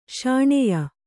♪ śaṇeya